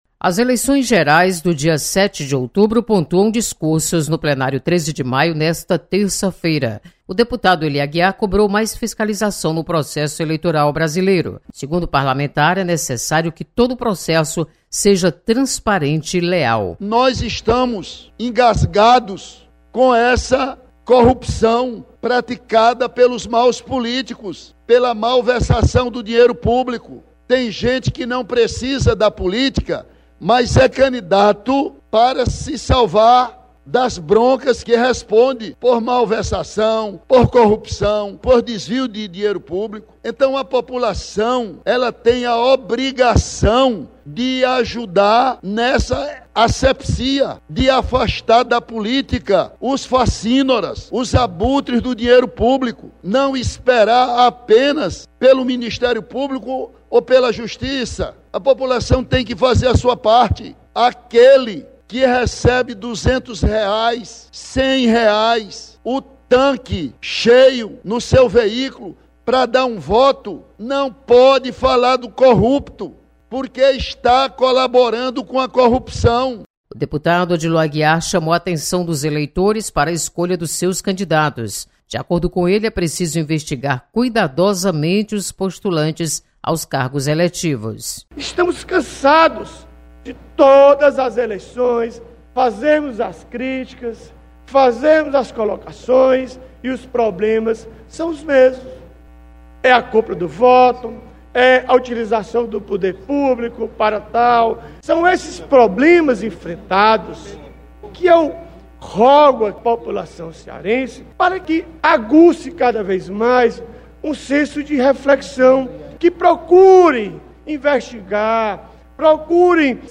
Eleições 2018 domina tema dos debates no Plenário 13 de Maio. Repórter